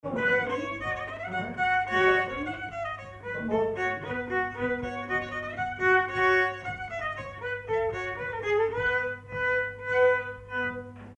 Mémoires et Patrimoines vivants - RaddO est une base de données d'archives iconographiques et sonores.
Avant deux
circonstance : bal, dancerie
Pièce musicale inédite